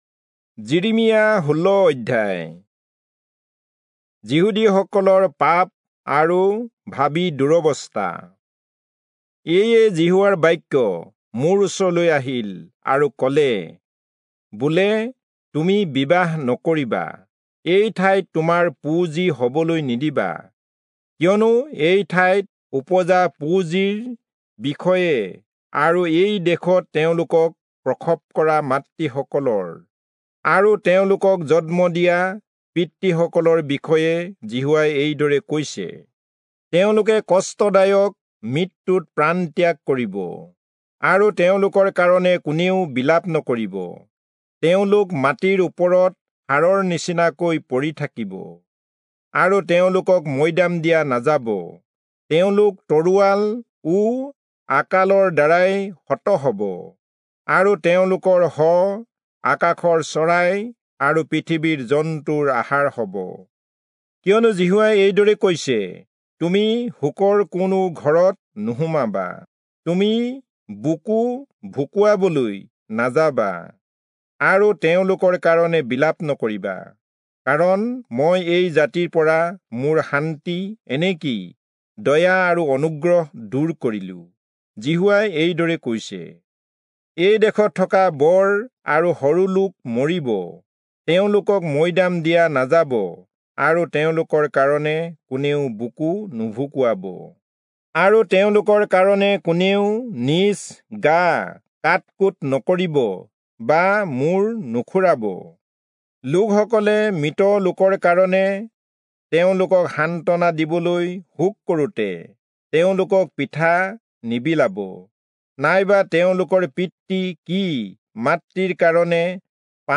Assamese Audio Bible - Jeremiah 43 in Kjv bible version